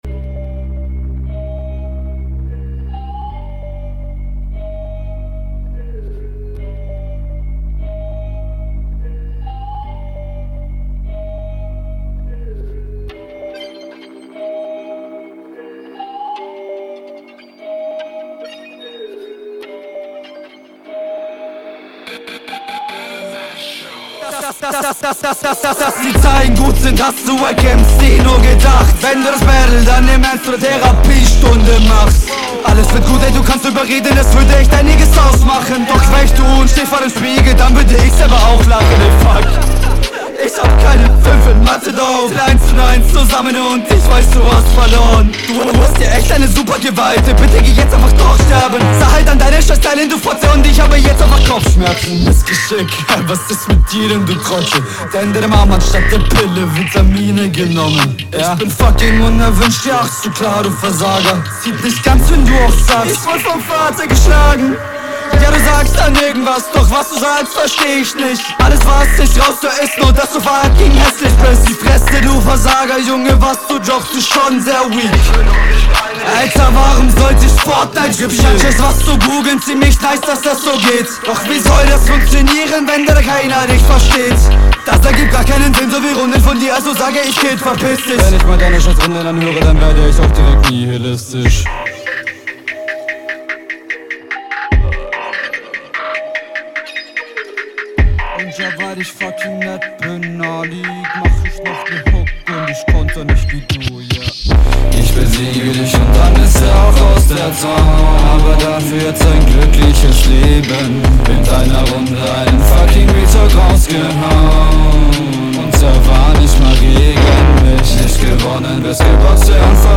Flow: Der Stimmeinsatz ist sehr passend.
Der Flow gefällt mir sehr gut und ist der beste der 4 Runden.
Flow: Um einiges besser als der von deinem Gegner.